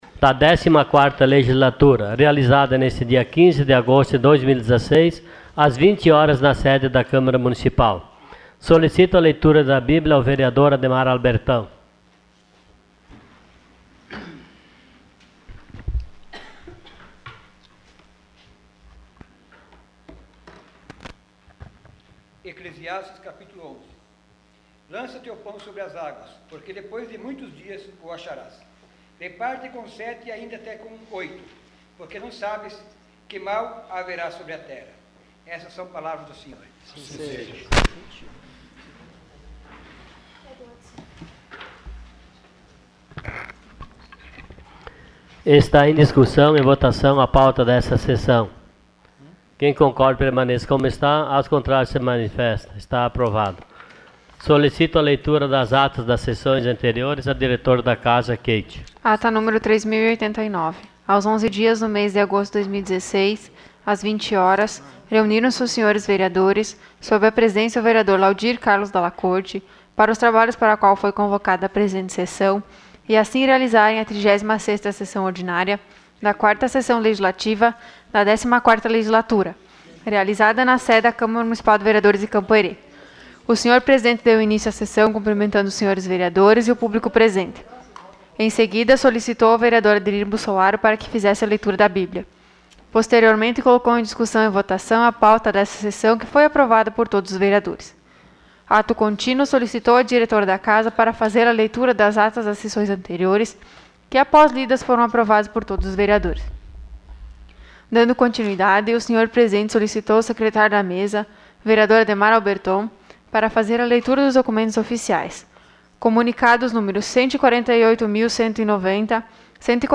Sessão Ordinária dia 15 de agosto de 2016.